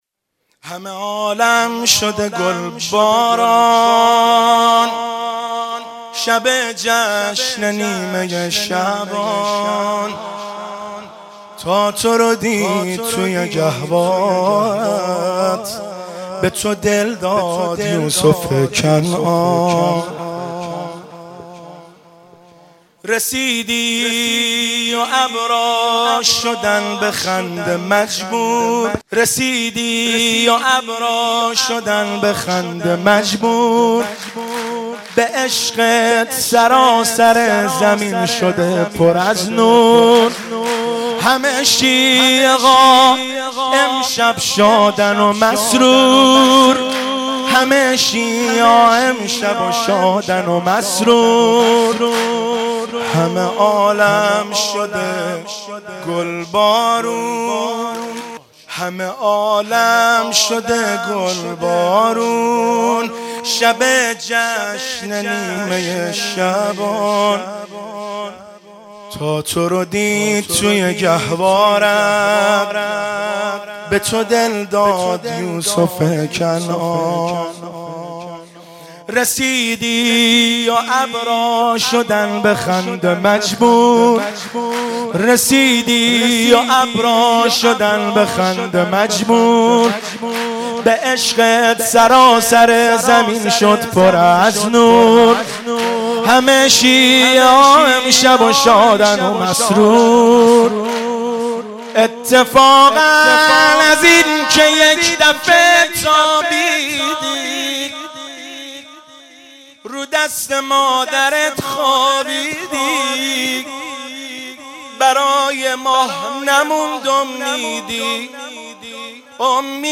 شب هفتم رمضان95، حاج محمدرضا طاهری